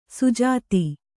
♪ sujāti